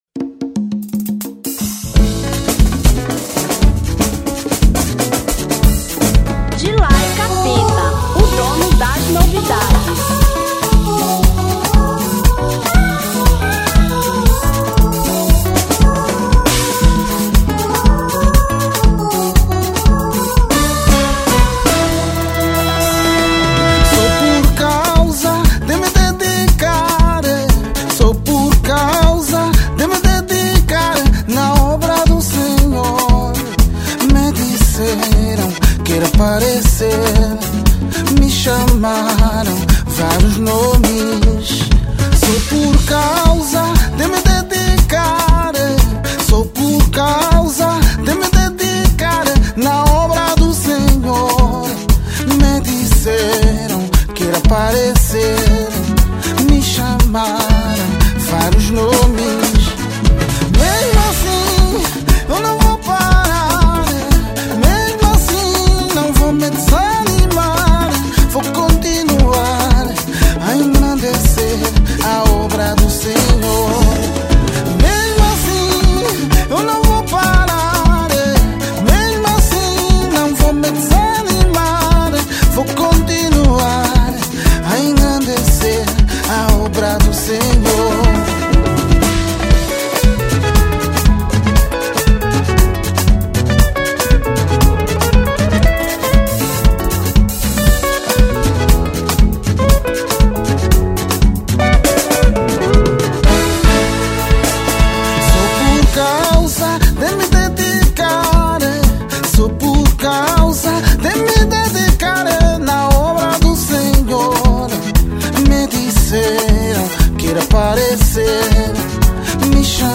Semba